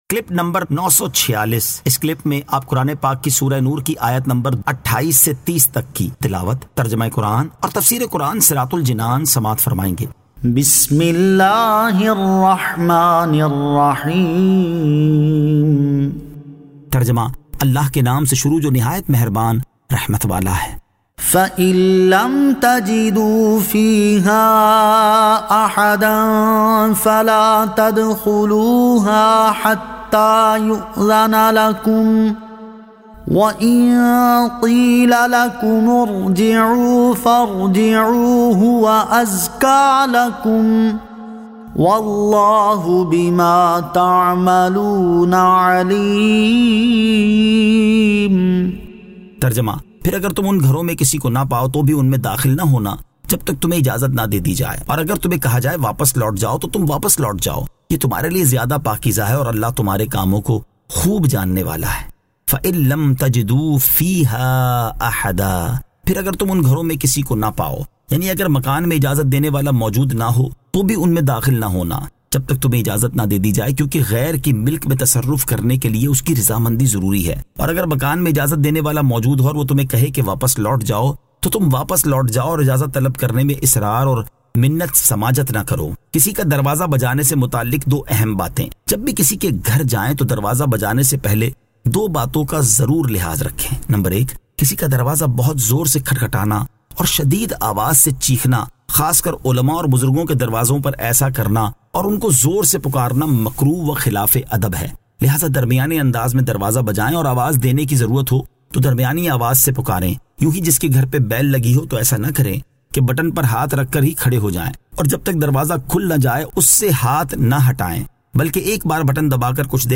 Surah An-Nur 28 To 30 Tilawat , Tarjama , Tafseer